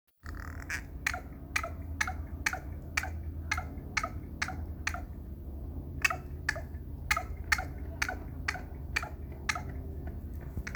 Corneille noire, chant II
CorneilleNoire-Chant_003.mp3